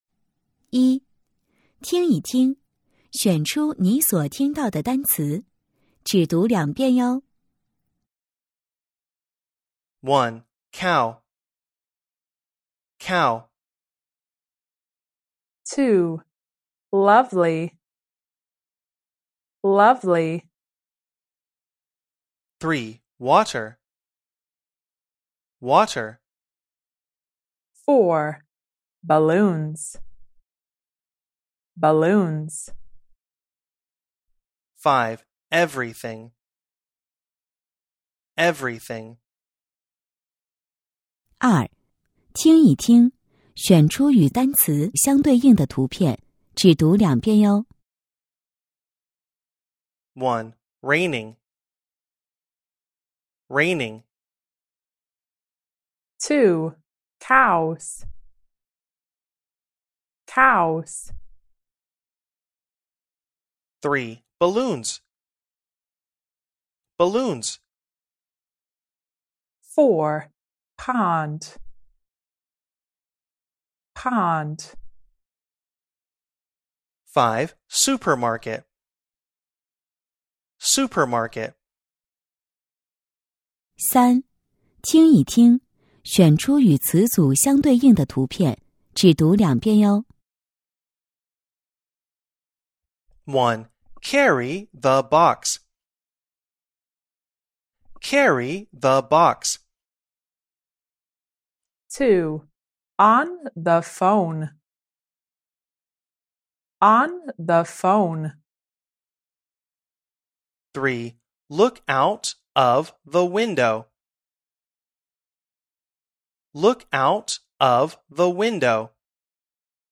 当前位置：首页 > 增值服务 > 听力MP3